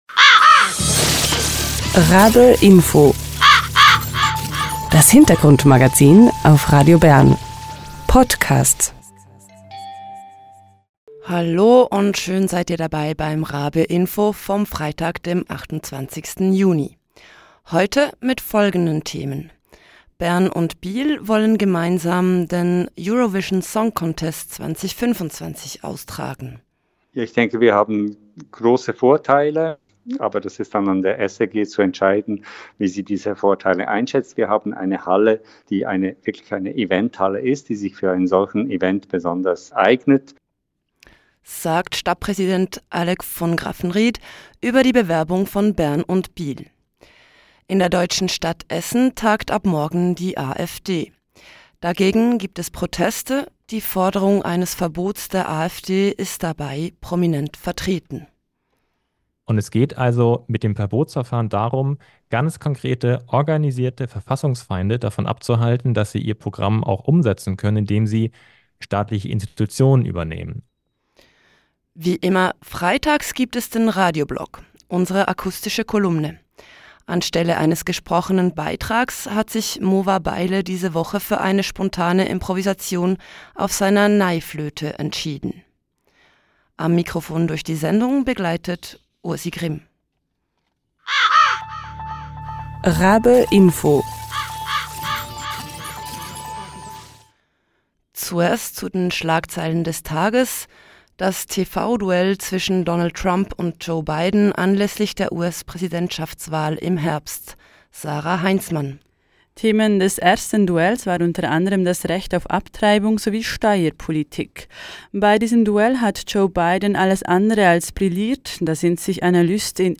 Stadtpräsident Alec von Graffenried spricht im Interview über die Bewerbung.
Letzte Woche hat sich ein Bündnis gebildet, das ein Verbot der AfD anstrebt. Im Interview hören wir, was ein solches Verbot bringen soll.